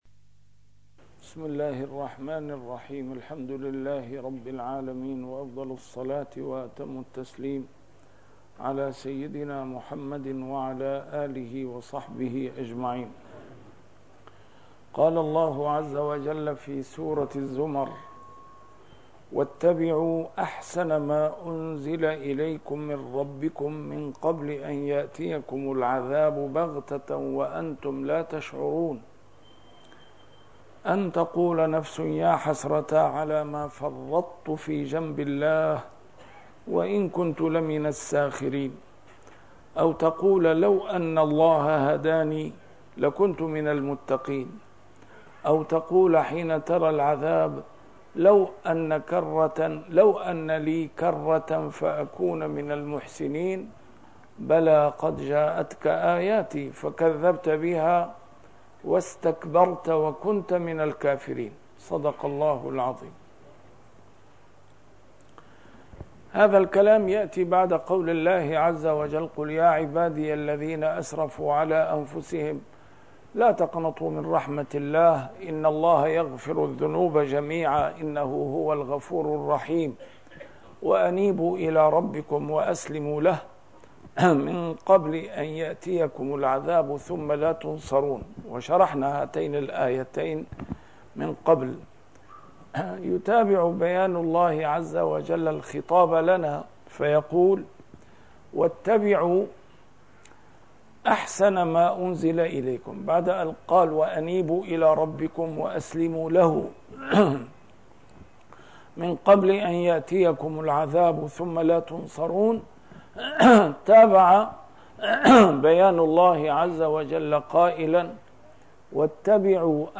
A MARTYR SCHOLAR: IMAM MUHAMMAD SAEED RAMADAN AL-BOUTI - الدروس العلمية - تفسير القرآن الكريم - تسجيل قديم - الدرس 501: الزمر 55-60